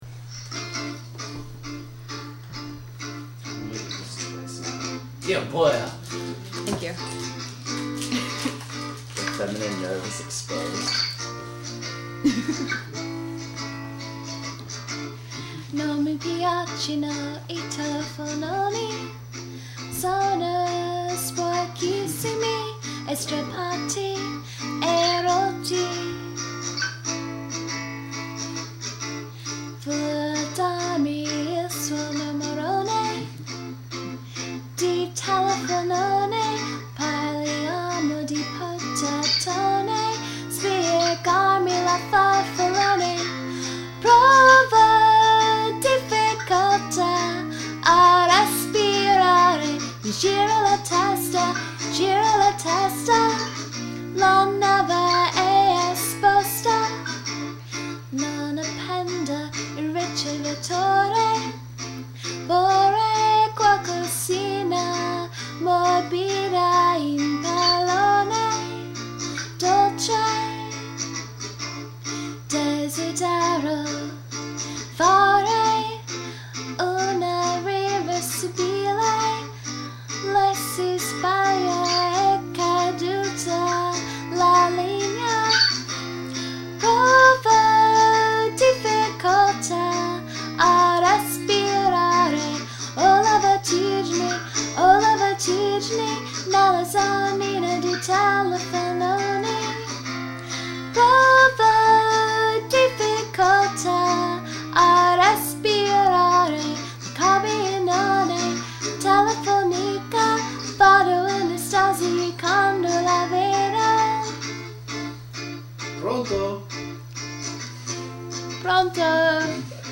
Gin 'n' Jameson fueled melody demo